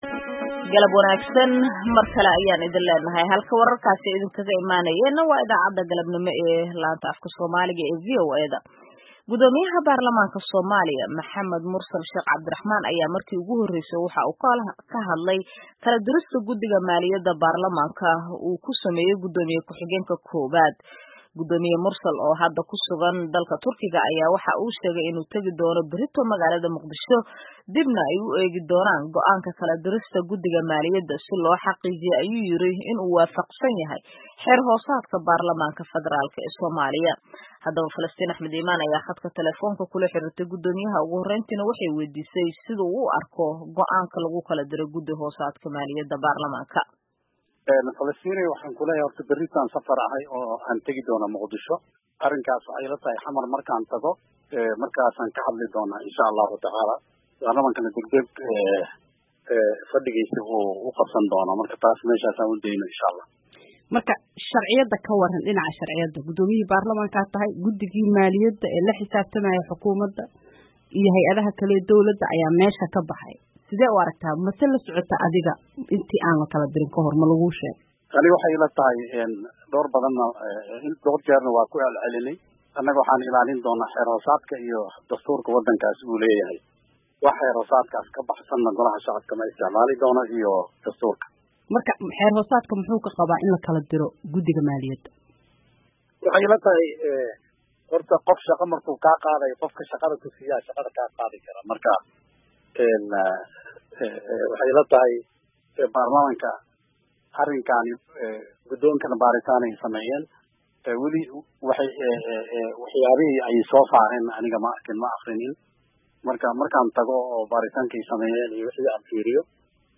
Wareysi: Guddoomiyaha baarlamanka Soomaaliya